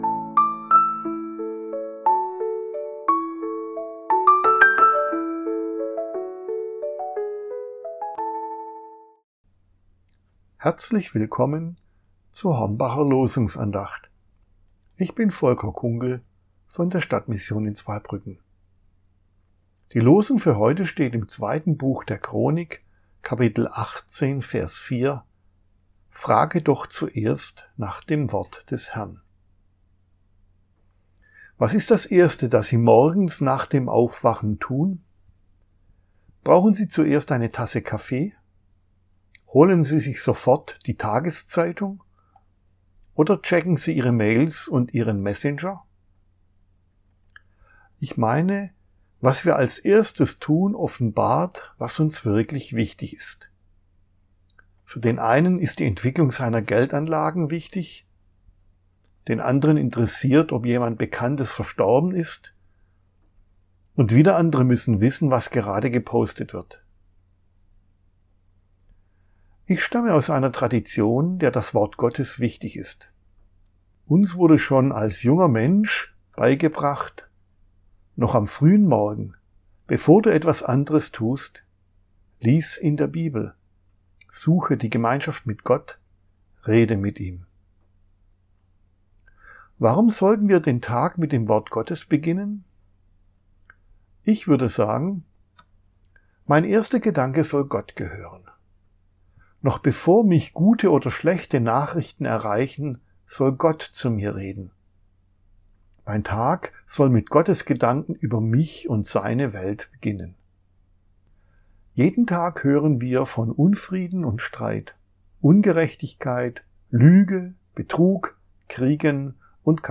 Losungsandacht für Montag, 19.01.2026 – Prot. Kirchengemeinde Hornbachtal mit der prot. Kirchengemeinde Rimschweiler